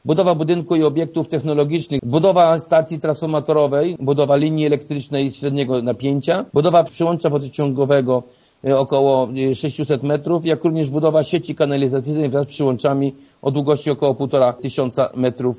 „Firma z Lublina, która realizowała tę inwestycję, musiała wykonać łącznie kilka zadań” - mówi wójt Jerzy Kędra: